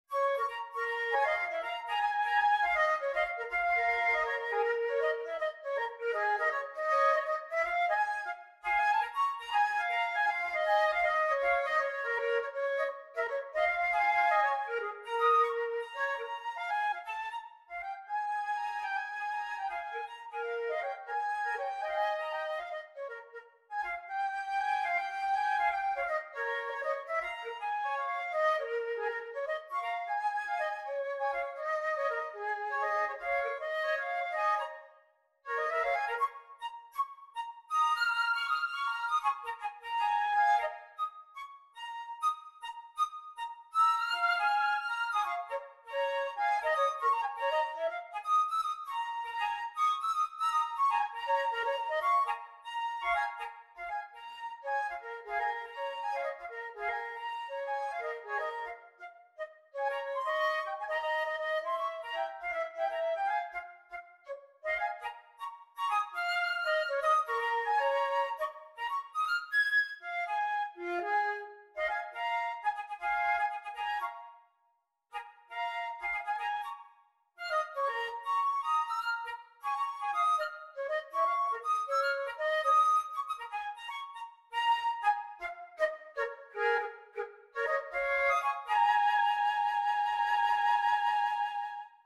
Gattung: Für 2 Flöten
Besetzung: Instrumentalnoten für Flöte